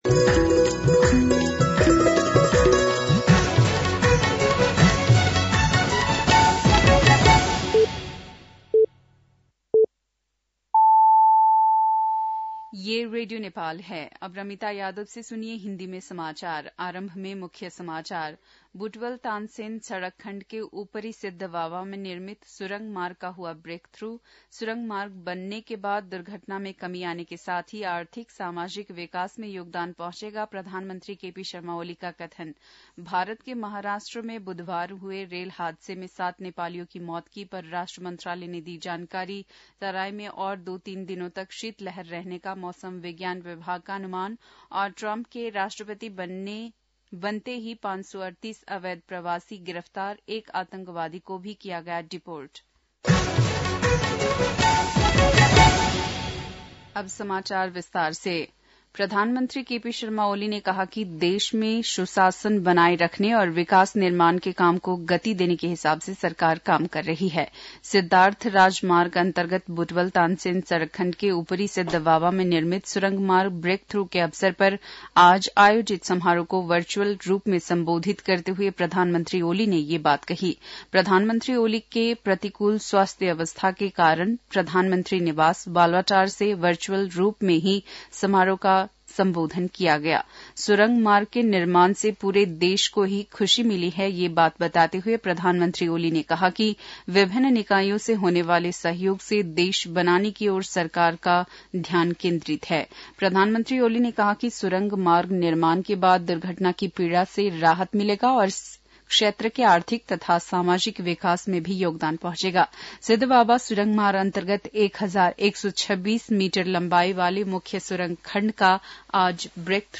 बेलुकी १० बजेको हिन्दी समाचार : १२ माघ , २०८१